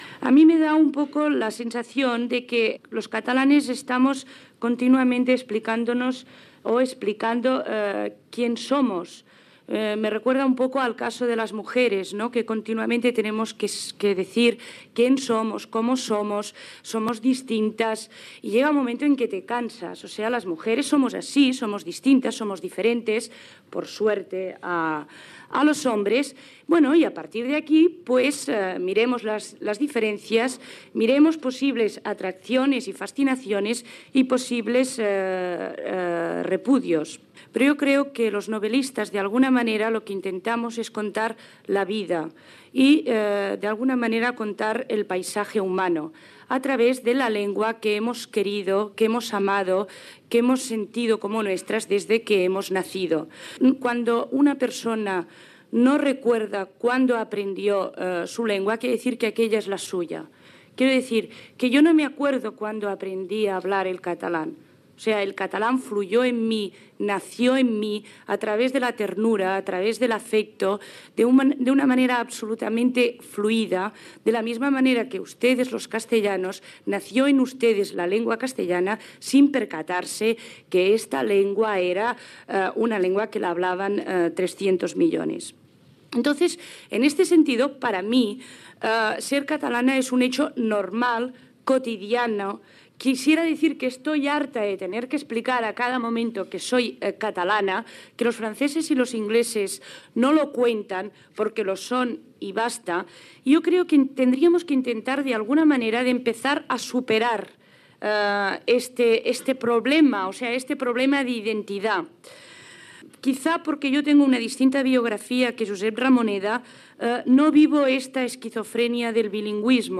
Participació de l'escriptora Montserrat Roig a "Relaciones de las Culturas Castellana y Catalana : encuentro de intelectuales" fet a Sitges del 20 al 22 de desembre de 1981. La normalitat d'escriure en català i de ser catalana